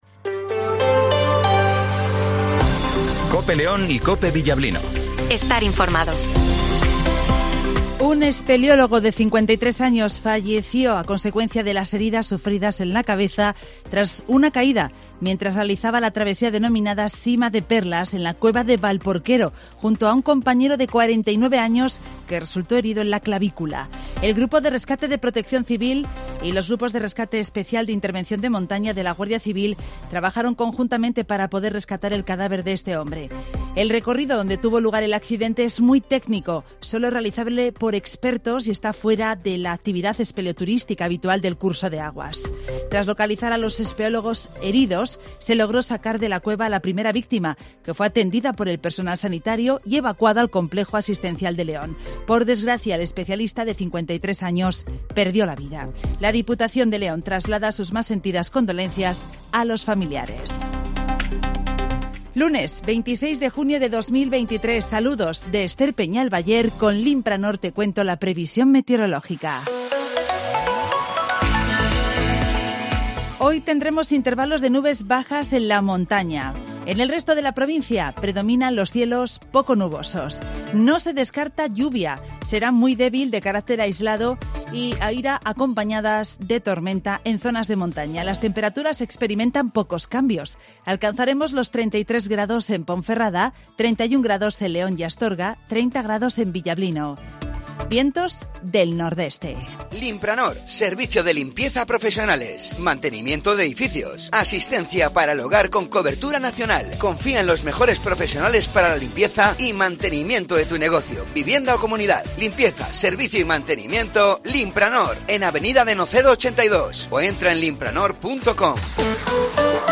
- Informativo Matinal 08:20 h